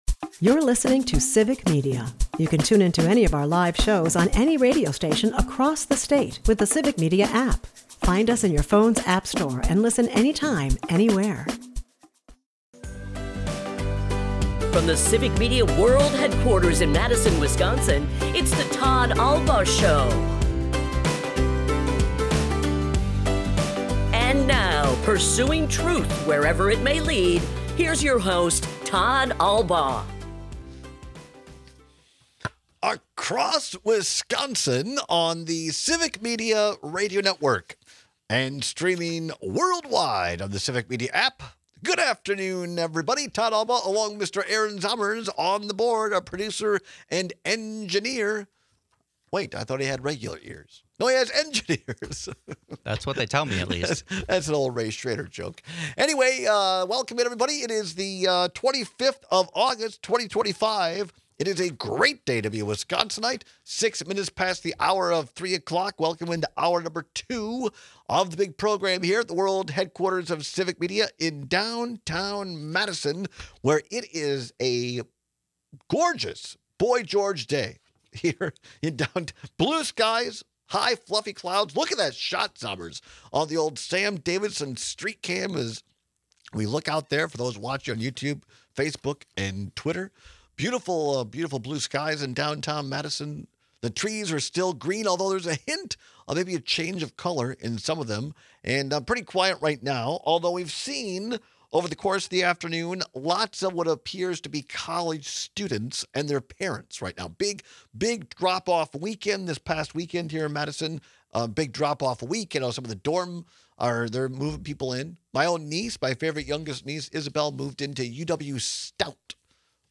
We take calls and texts on which salad puts the “ick” in picnic.